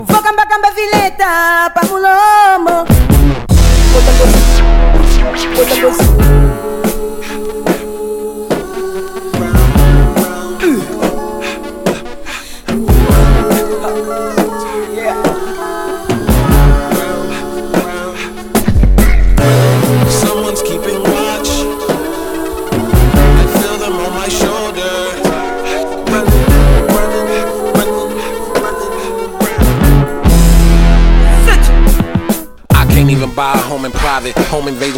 Жанр: Иностранный рэп и хип-хоп / Рок / Рэп и хип-хоп